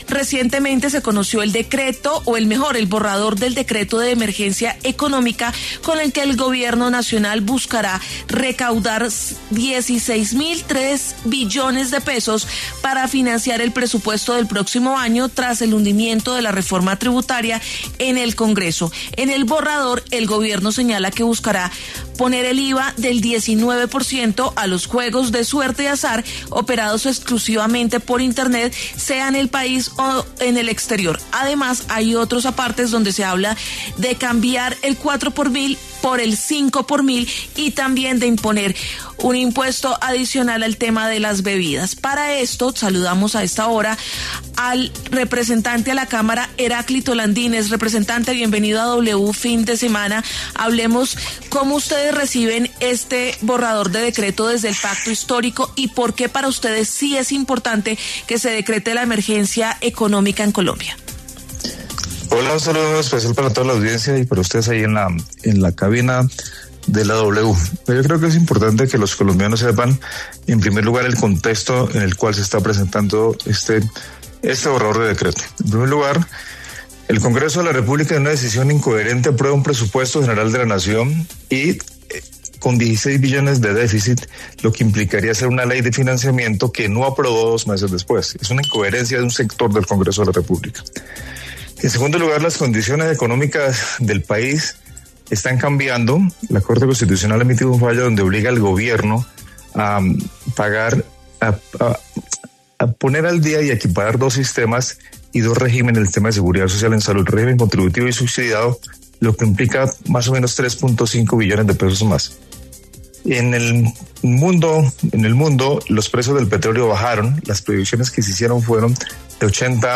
Los congresistas Heráclito Landínez y Carlos Meisel conversaron con W Fin de Semana sobre la intención del Gobierno de recaudar $16,3 billones para financiar el presupuesto del año 2026.